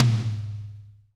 -DRY TOM 2-L.wav